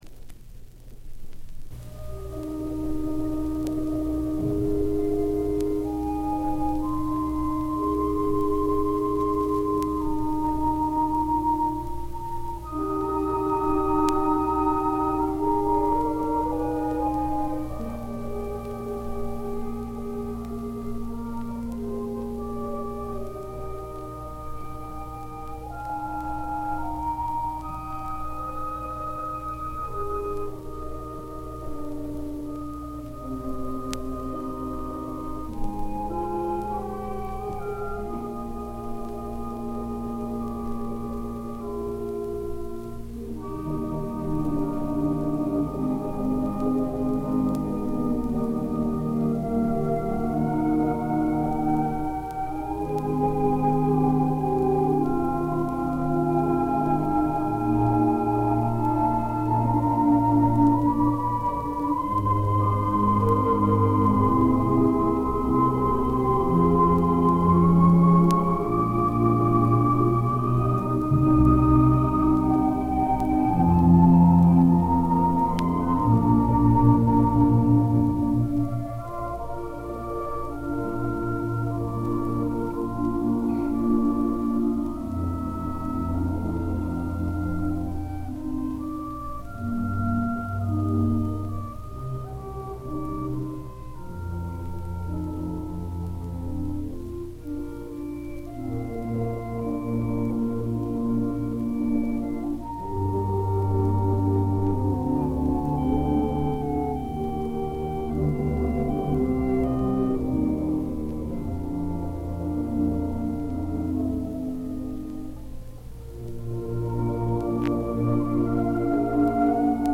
I would assume it is the old Lucien Cailliet arrangement, although the harp part may have been added to more closely match the orchestral score.
lhs-band-elsas-procession.mp3